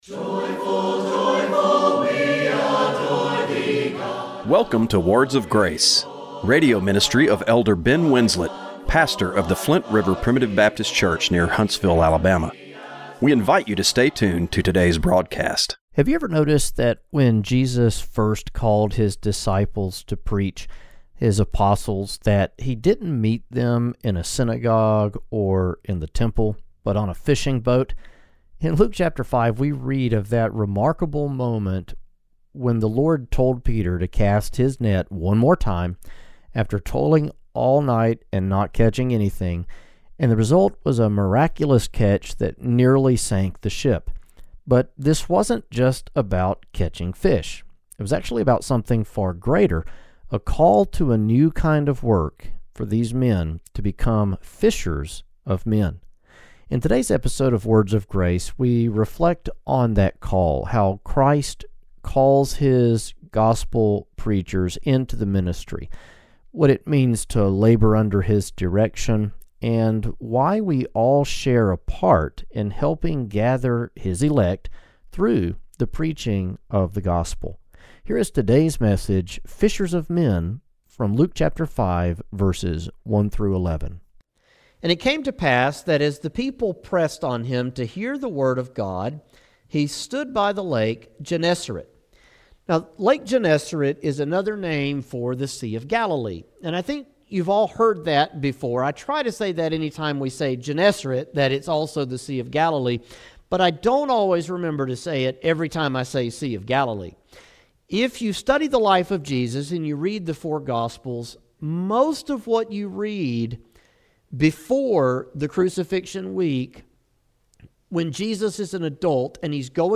Radio broadcast for November 2, 2025.